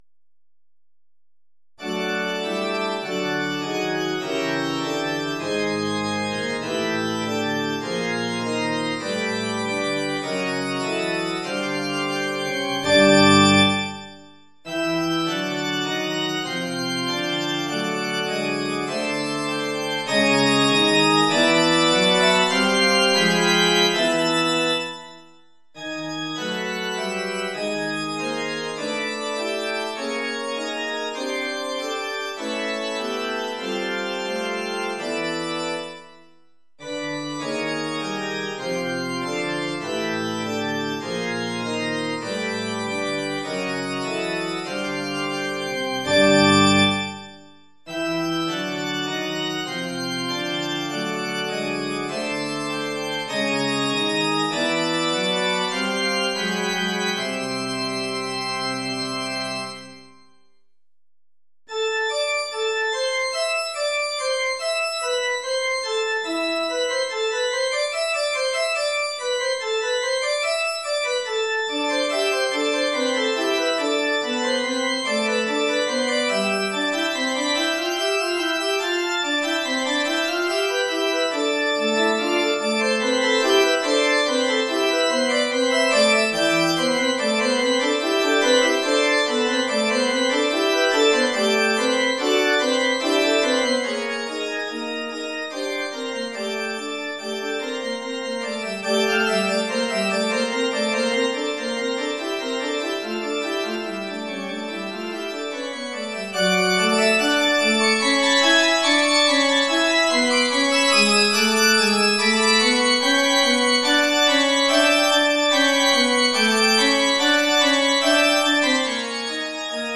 Oeuvre pour orgue.